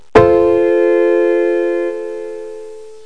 pianoch5.mp3